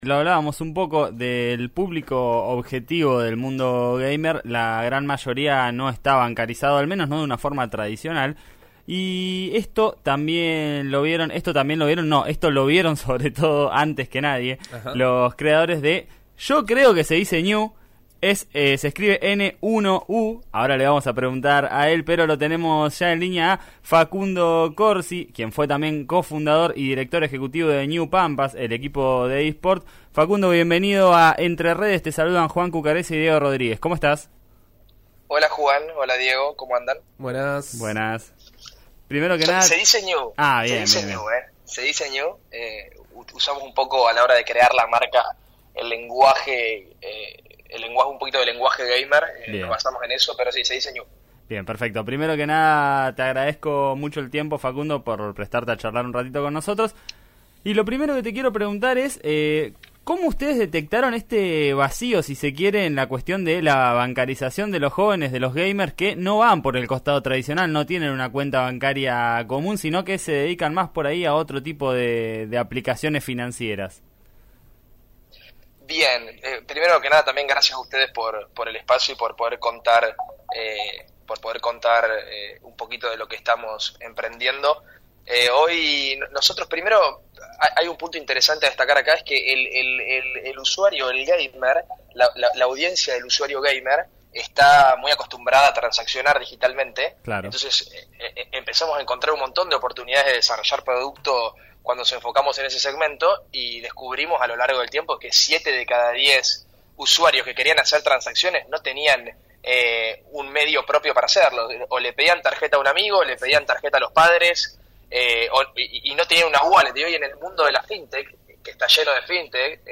habló con “Entre Redes” de RÍO NEGRO RADIO y explicó como surgió la idea.